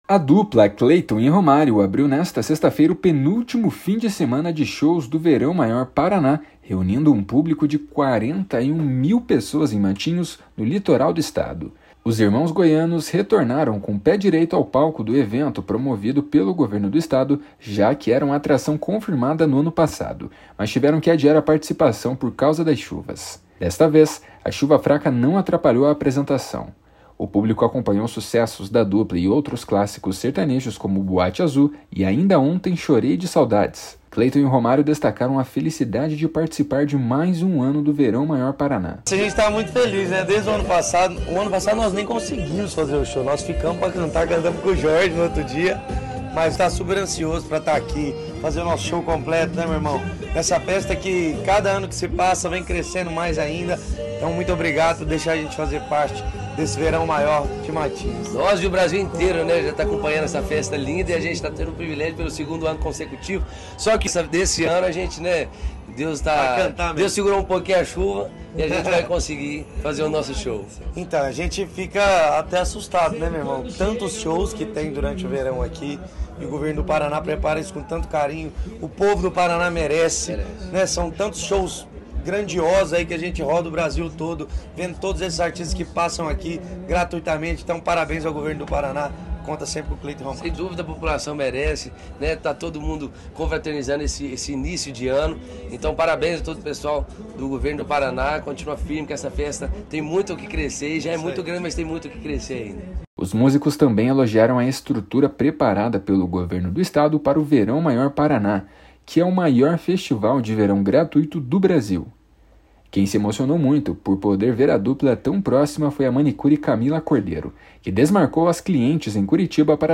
// SONORA CLEYTON E ROMÁRIO //